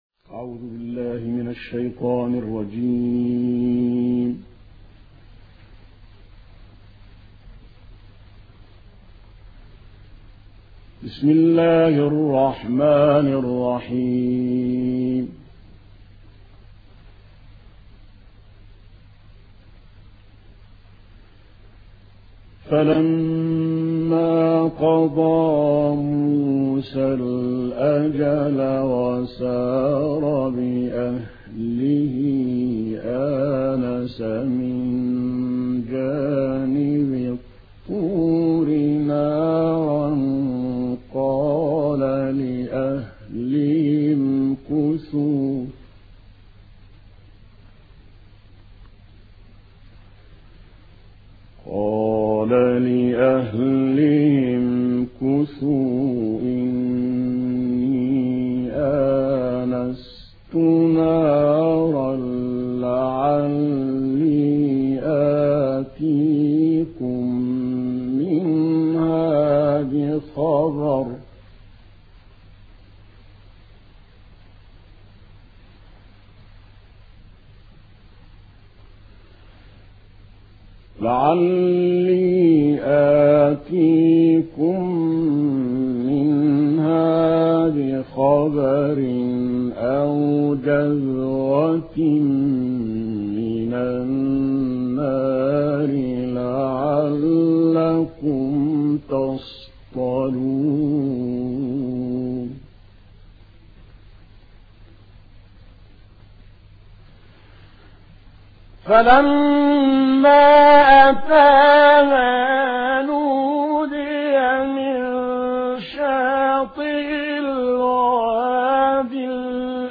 ما تيسر من سورة القصص القارئ الشيخ عبد العظيم زاهر